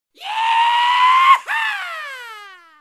goofy yeha yeehaa yeeha yehaa ohio sigma sound effects
goofy-yeha-yeehaa-yeeha-yehaa-ohio-sigma